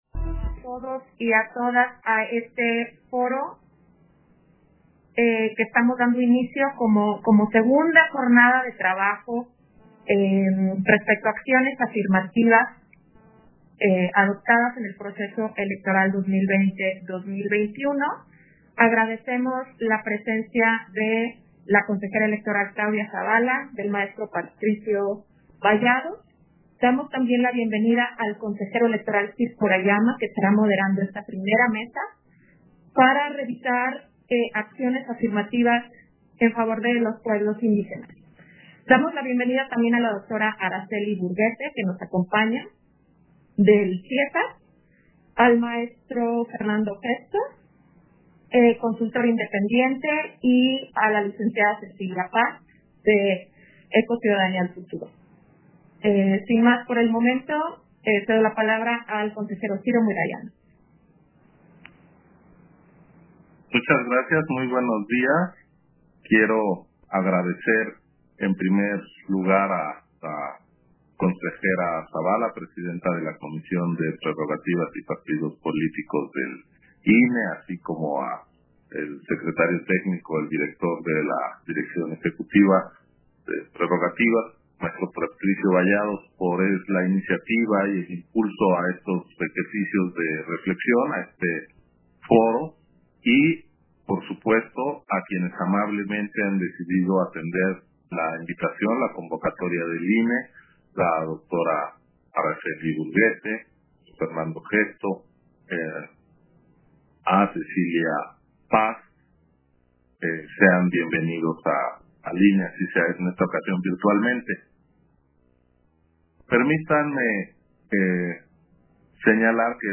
190821_AUDIO_MESA-1-FORO-EVALUACION-DE-PROSPECTIVA-DE-LAS-ACCIONES-AFIRMATIVAS - Central Electoral